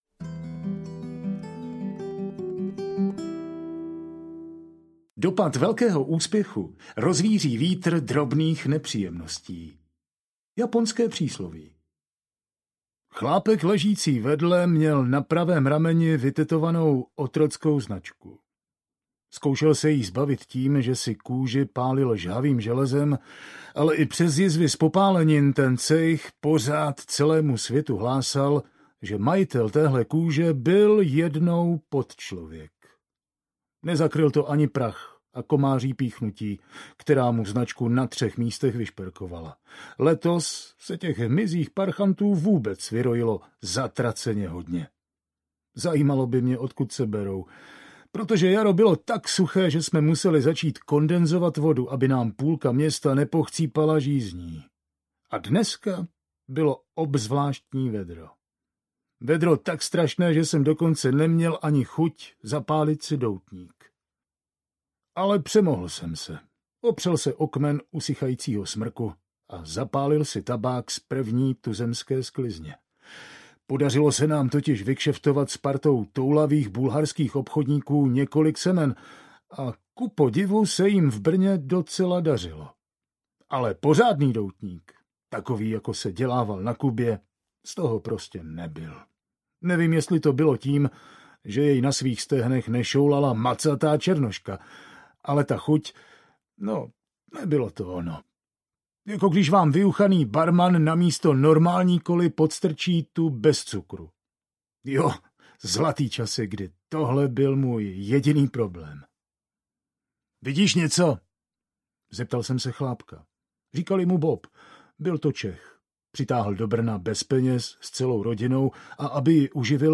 Poločas rozpadu audiokniha
Ukázka z knihy